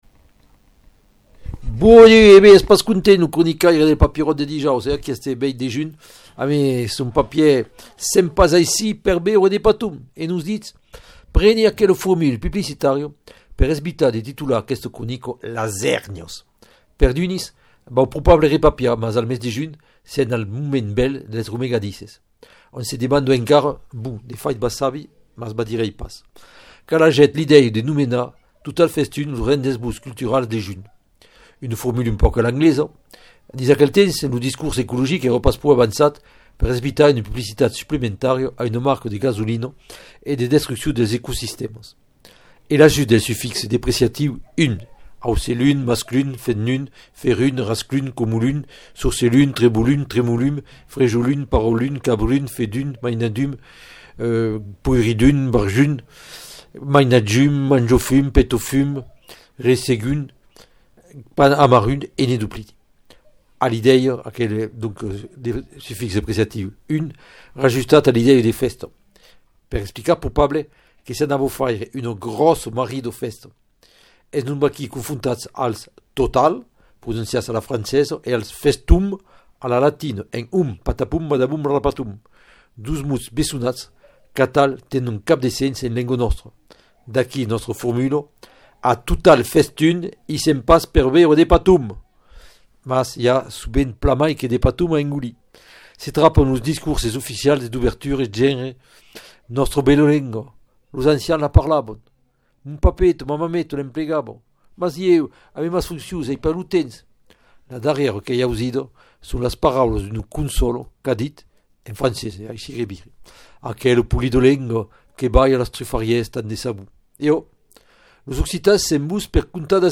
Lo Papieròt del dijòus sus ràdio Lengadòc